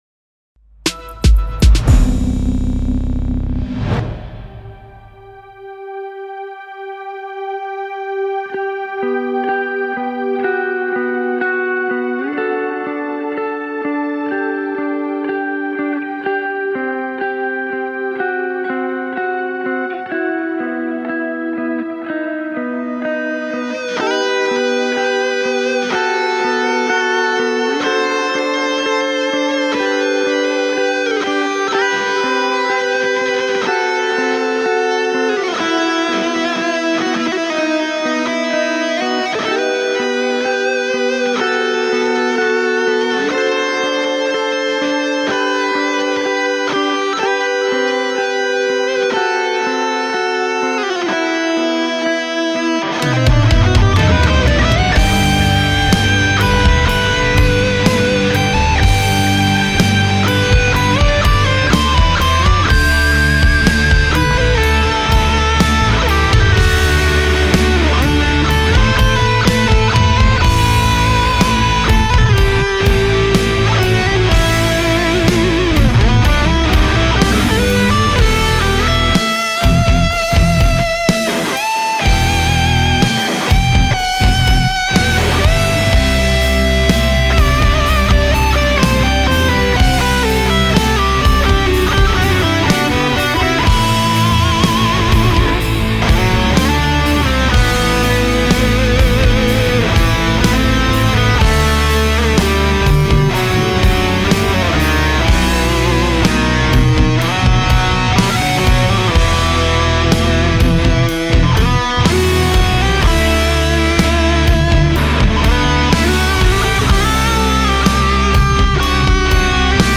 rock music
راک ایرانی